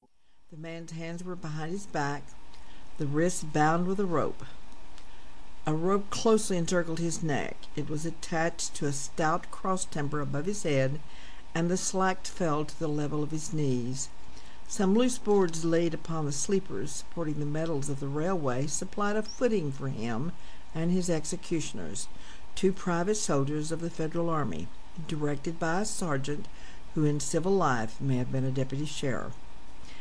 Audio Book
Title ... Fiction